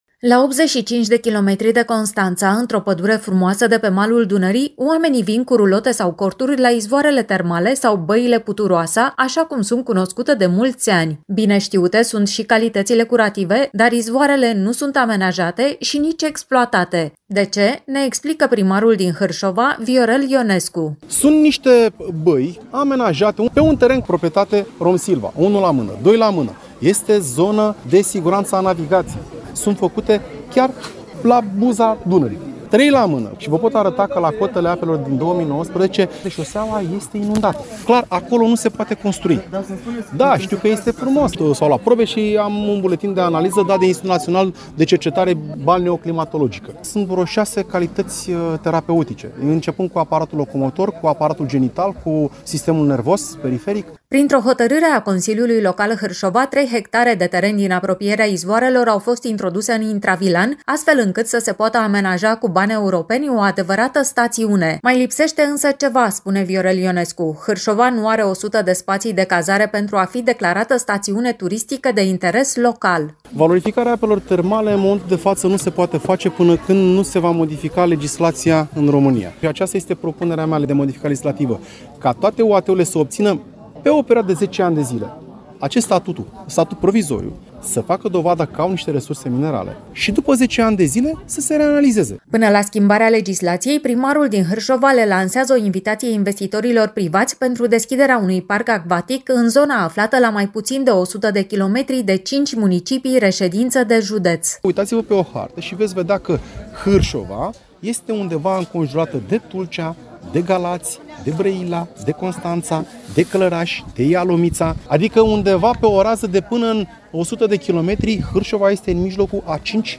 Primarul orașului Hârșova, Viorel Ionescu, spune că este nevoie de modificarea legislației pentru ca această comoară naturală să nu se ducă pe apa Sâmbetei.